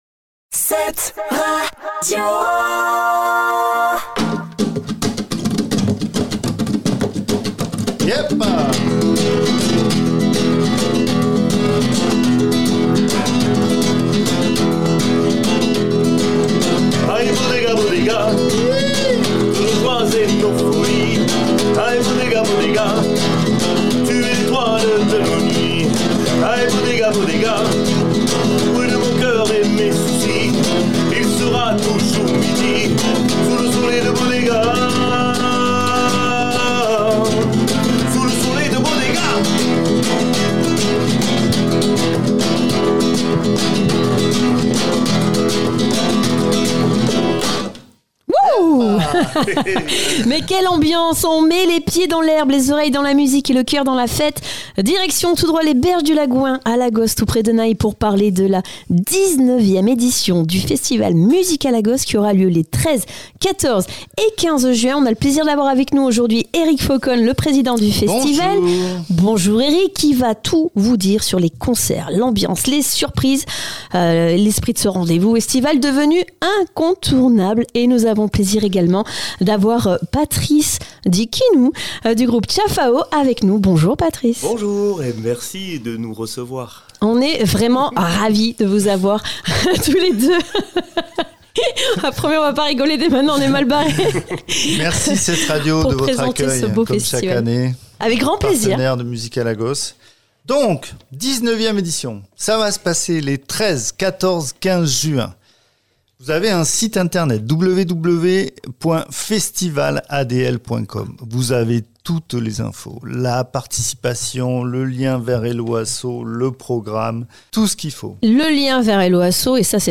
Podcast Interview Musicalagos